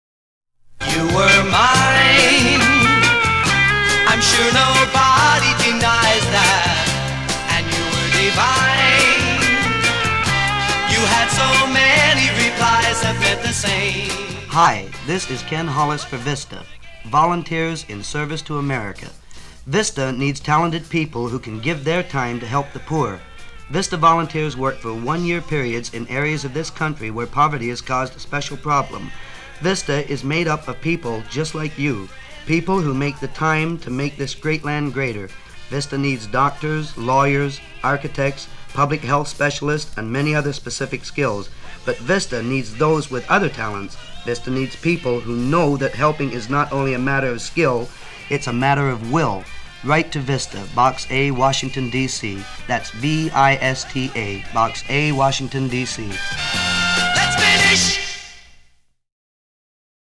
(Public Service Announcements for